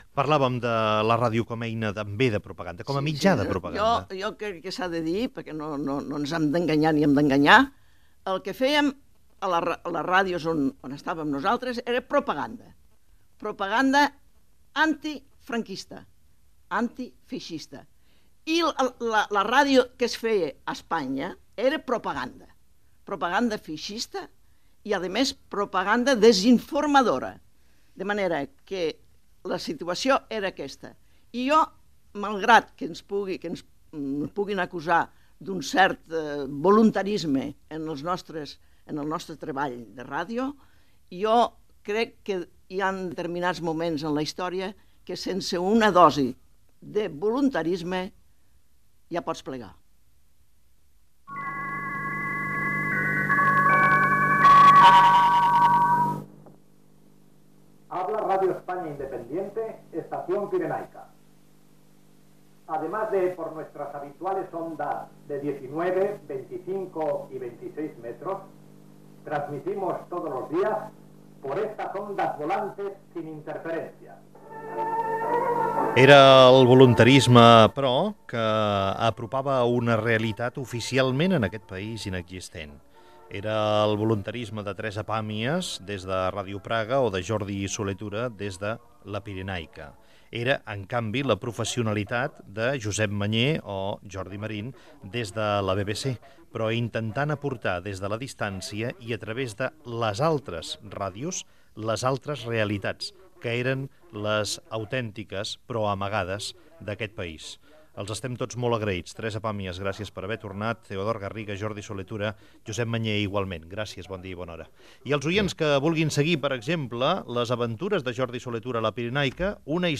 Fragment d'una taula rodona sobre els 75 anys de la ràdio amb Jordi Solé Tura
Info-entreteniment